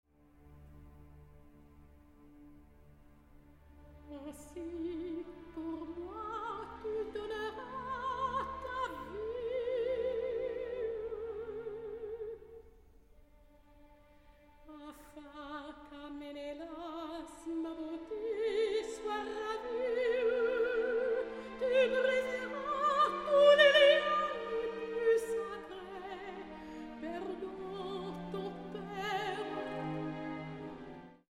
Super Audio CD
World premiere recording.